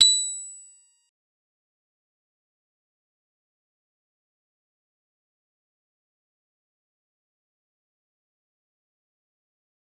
G_Musicbox-B8-mf.wav